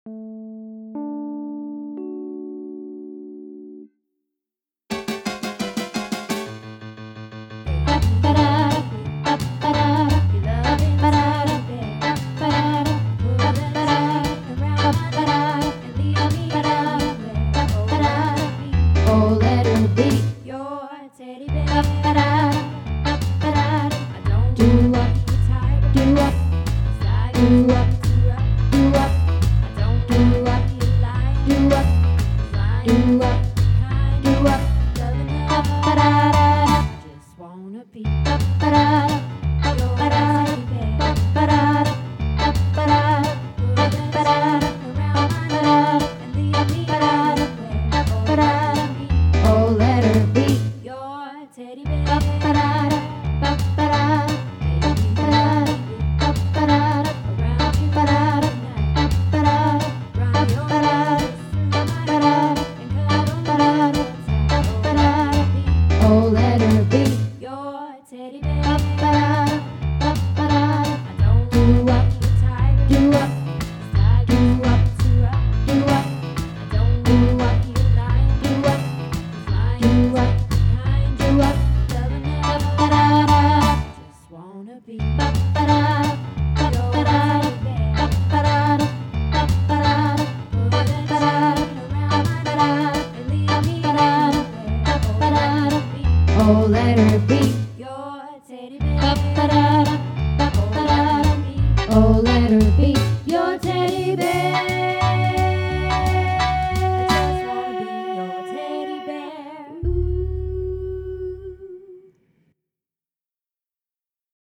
Teddy Bear -Tenor